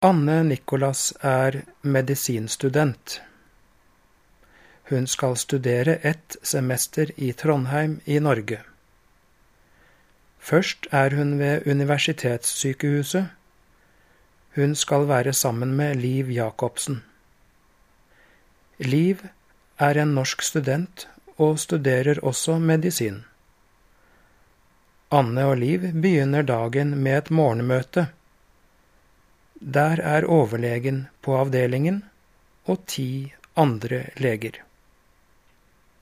Diktat
Diktaten leses tre ganger: